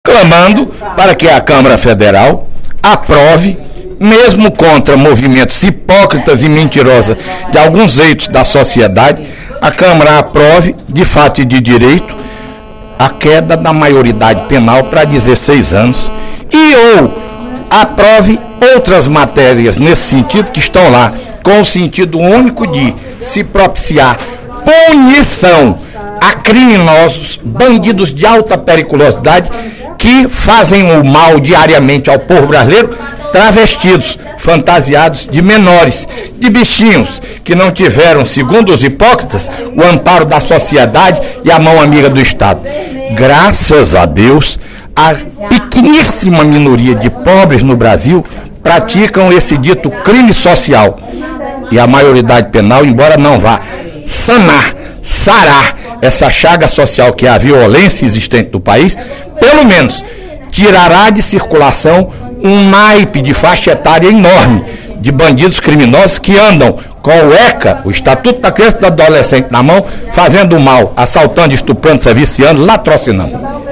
O deputado Fernando Hugo (SD) defendeu, durante o primeiro expediente da sessão desta quarta-feira (17/06), a aprovação da proposta, que tramita no Congresso Nacional, que reduz a maioridade penal para 16 anos. De acordo com o parlamentar, a manutenção da atual legislação é “a aceitação do crime social”.